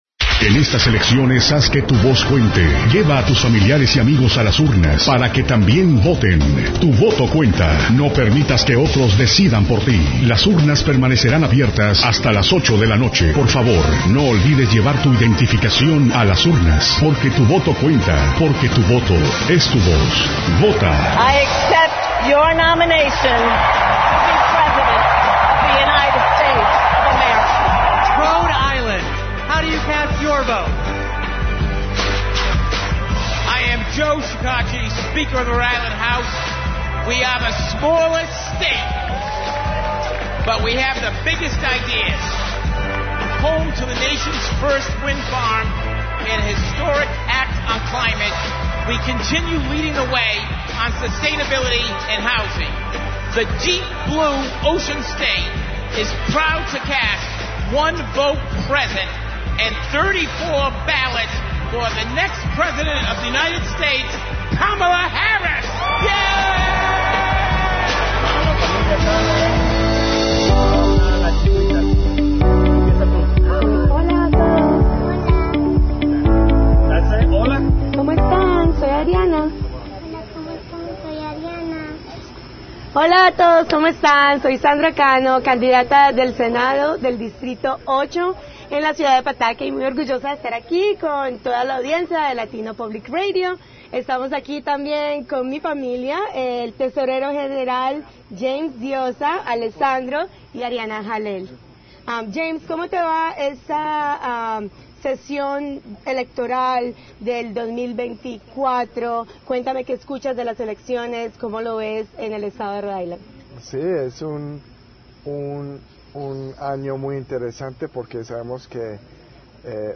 Commentaries with RI General Treasurer, James Diossa and State Senator Sandra Cano at LPR’s Political BBQ 2024
This interview is in the Spanish language only.